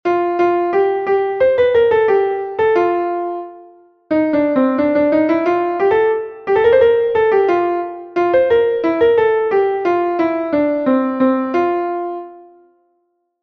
Entoación a capella